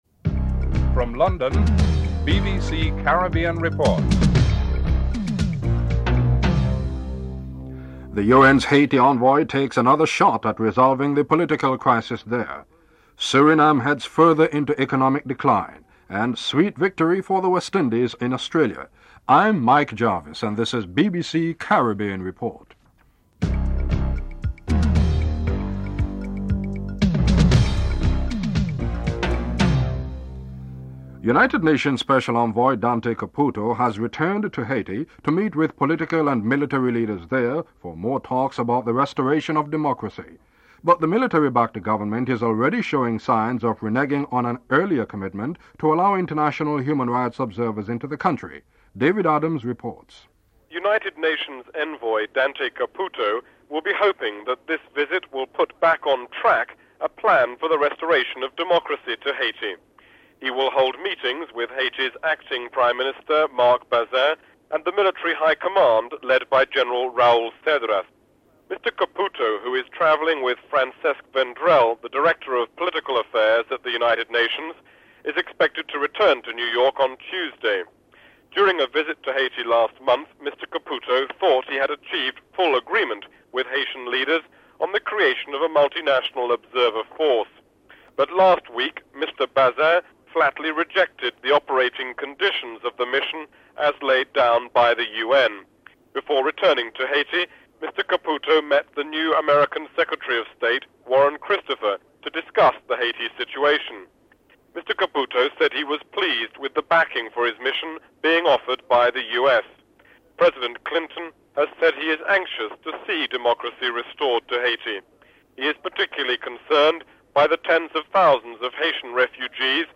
The British Broadcasting Corporation
Headlines with anchor